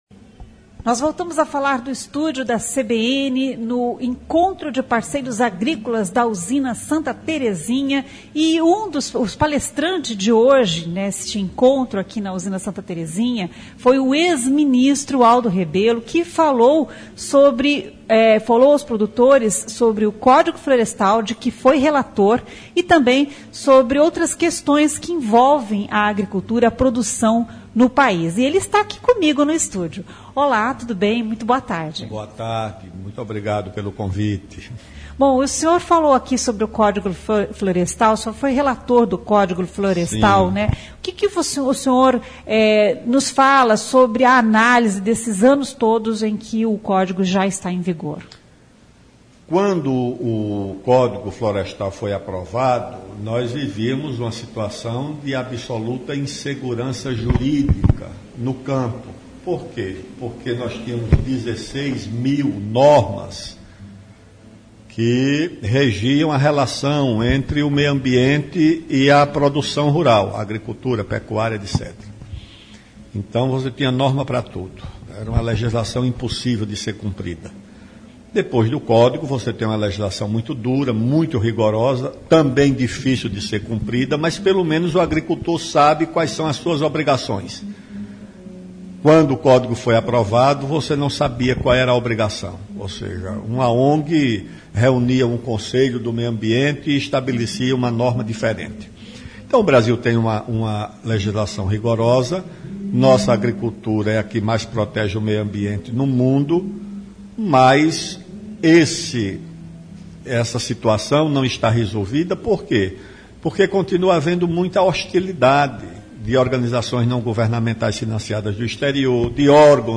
O ex-ministro Aldo Rebelo foi palestrante nesta quinta-feira (13) no Encontro de Parceiros Agrícolas da Usina Santa Terezinha